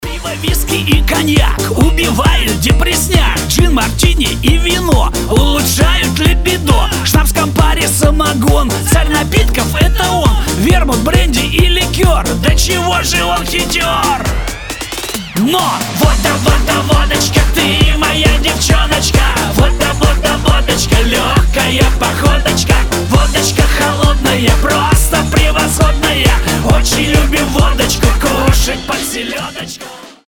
• Качество: 320, Stereo
позитивные
веселые
прикольные